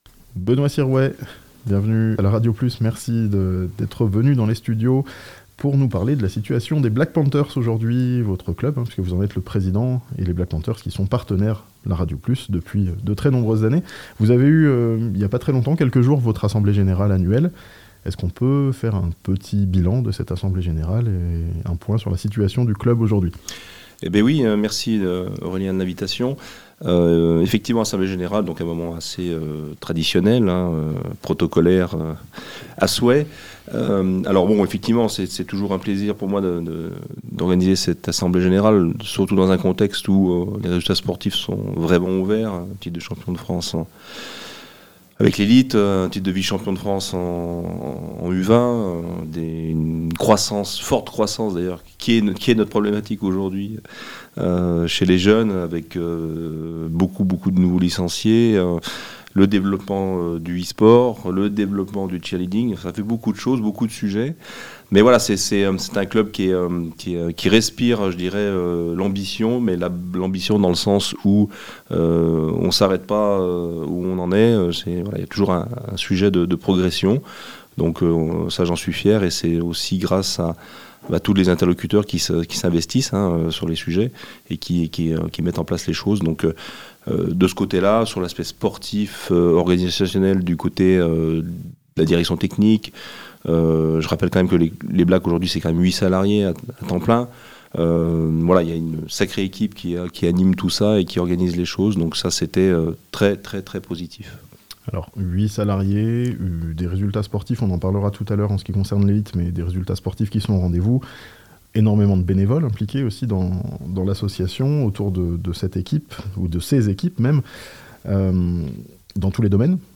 Avant 2 matchs capitaux, un point sur la situation des Black Panthers de Thonon (interview)